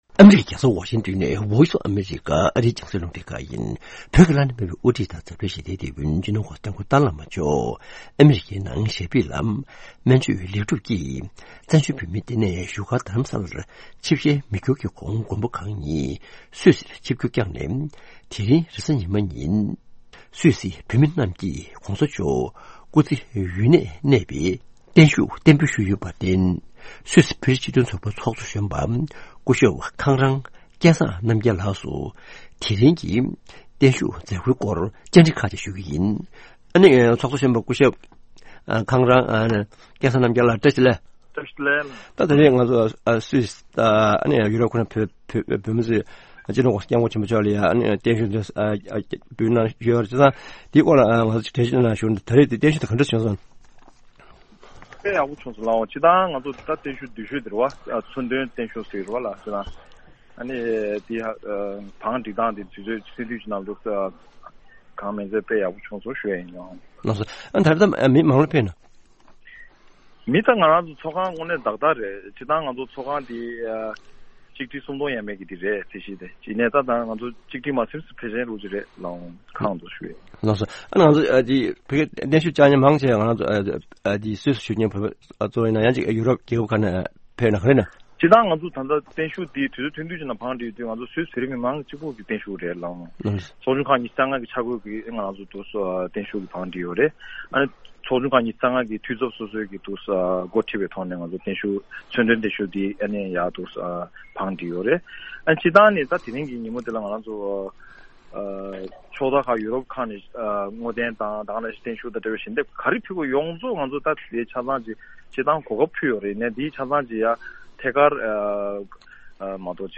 བརྟན་བཞུགས་སྐོར་བཅའ་འདྲི་ཞུས་པ་ཞིག་གསན་རོགས་གནང་།